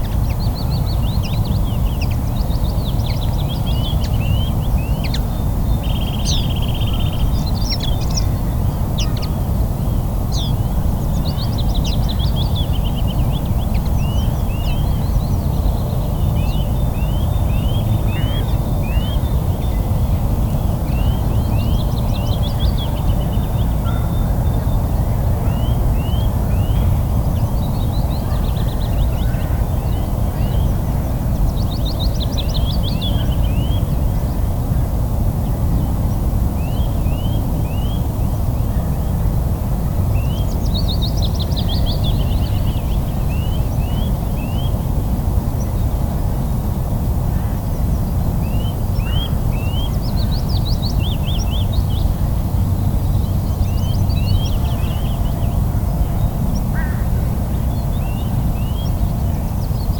drifting sand area Loonse en Drunense Duinen Netherlands 1017 am 250404_1067
Category 🌿 Nature
ambiance ambience ambient atmospheric background-sound birds calm drifting-sand-dunes sound effect free sound royalty free Nature